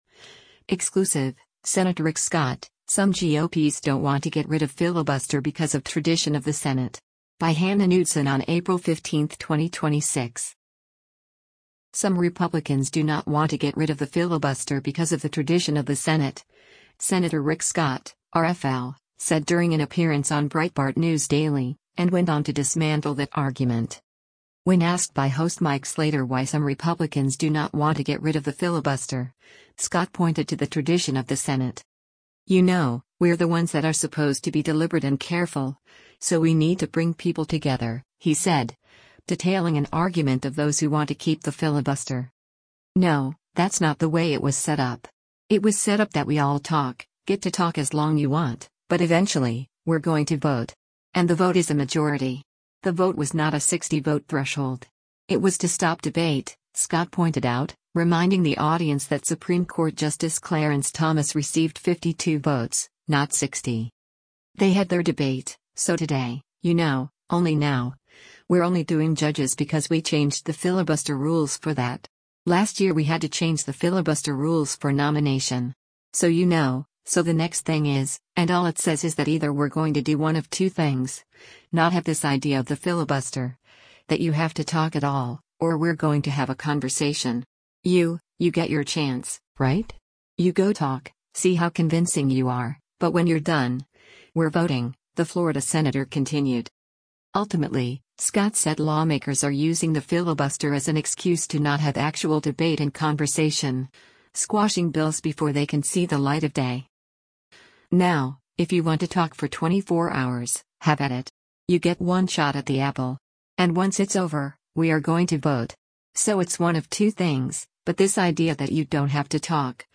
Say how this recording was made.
Breitbart News Daily airs on SiriusXM Patriot 125 from 6:00 a.m. to 9:00 a.m. Eastern.